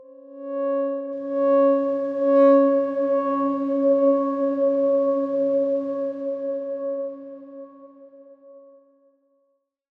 X_Darkswarm-C#4-pp.wav